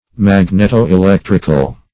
\Mag`net*o-e*lec"tric*al\, a. (Physics)